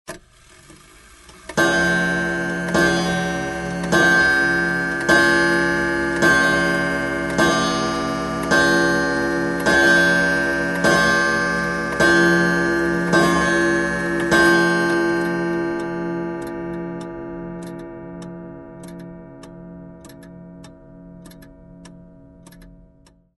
Download Halloween sound effect for free.